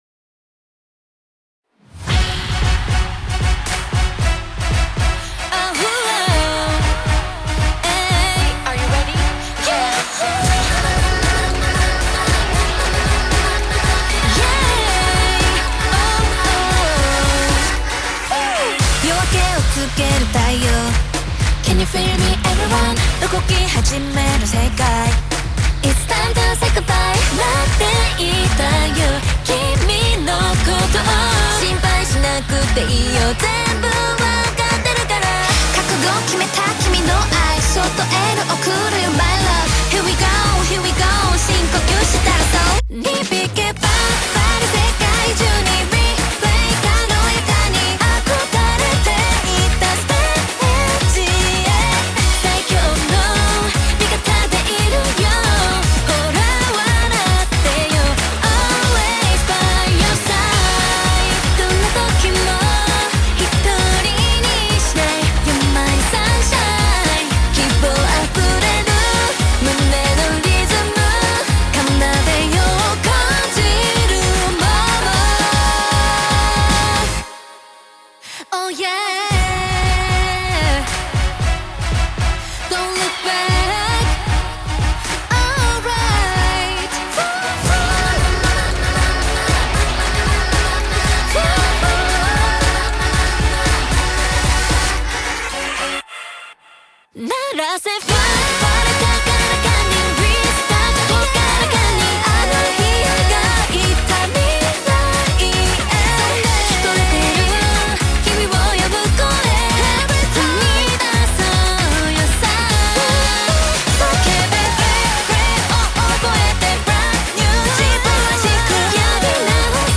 BPM115-230
Very upbeat and peppy, I think!